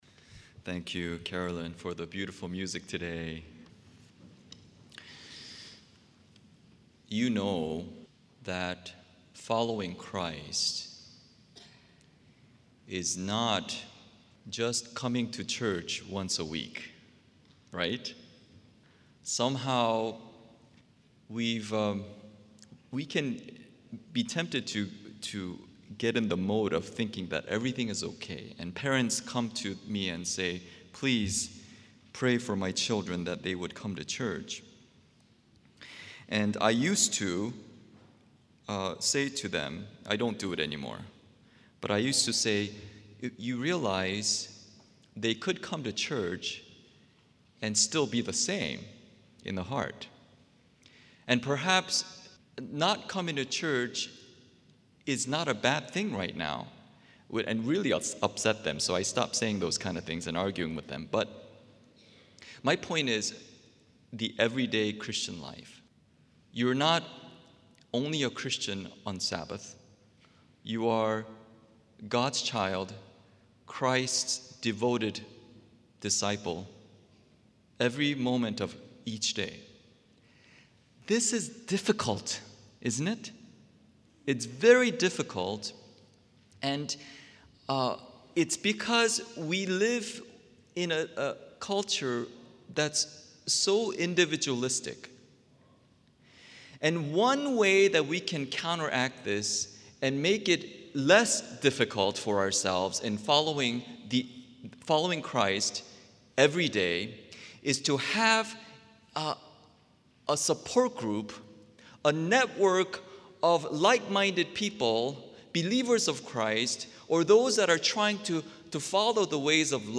SERMONS 2013